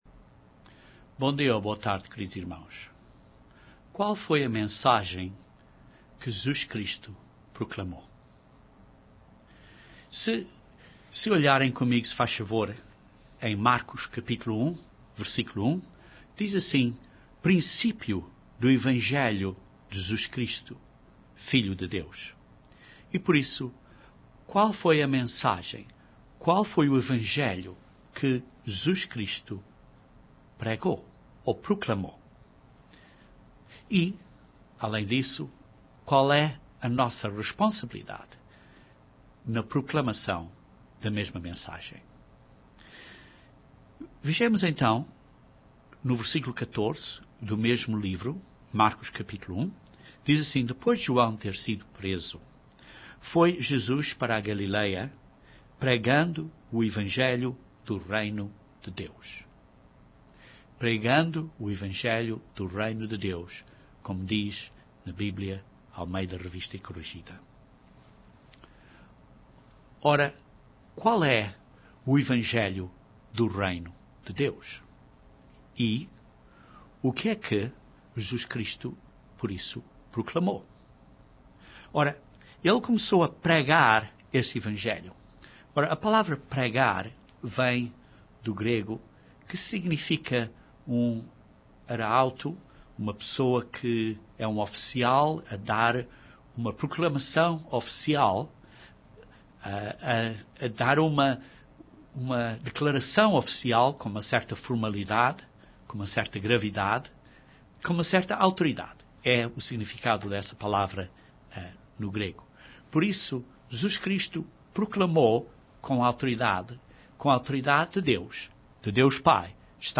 Este sermão aborda estes assuntos e depois encoraja-nos a desenvolver uma característica específica daqueles que são os filhos e filhas de Deus no Reino de Deus.